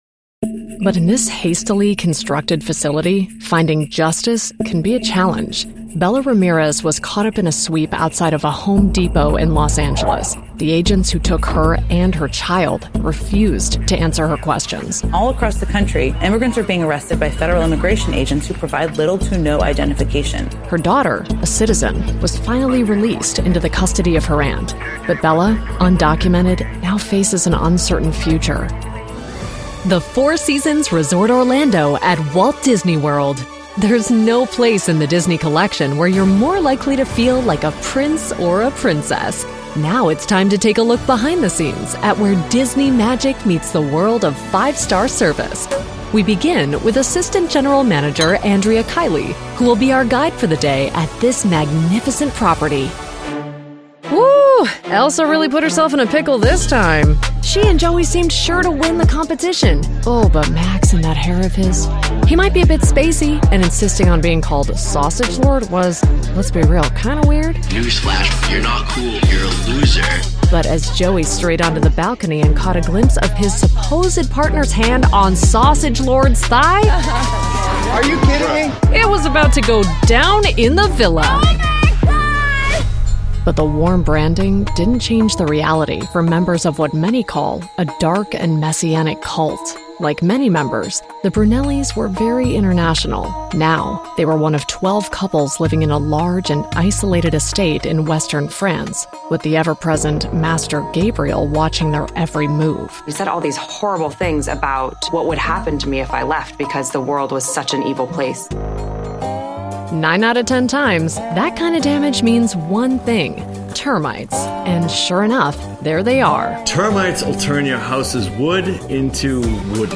Documentales
Sennheiser MKH 416, Neumann U87, Apollo Twin X
Cabina personalizada diseñada profesionalmente con nivel de ruido de -84 dB, techo y piso desacoplados, sin paredes paralelas